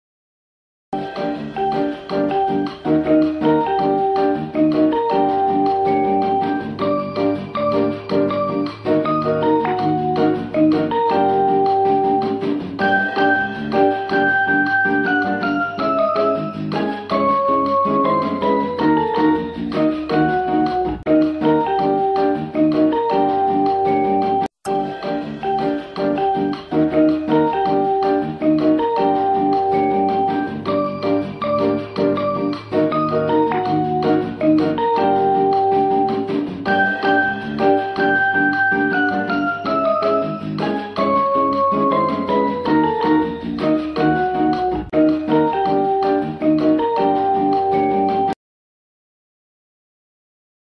Elevator Music